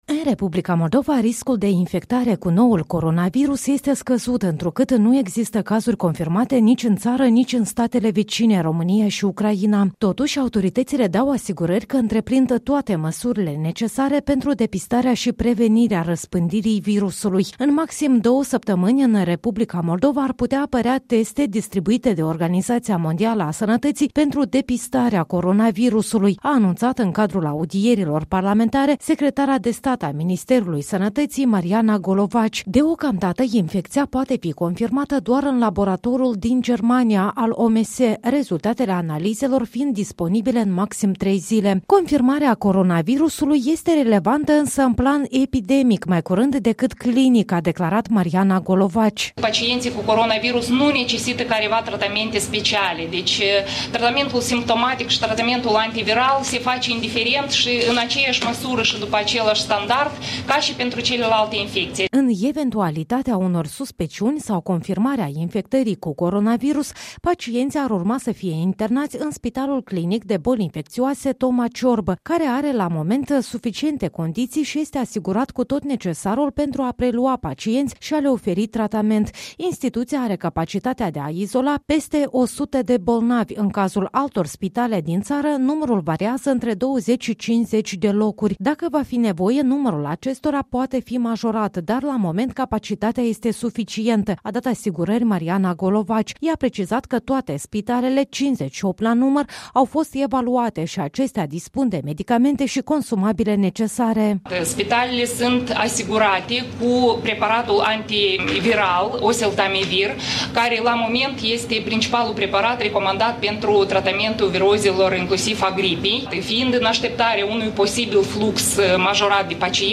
O relatare despre reacția autorităților moldovene, așa cum a fost prezentată la comisia parlamentară.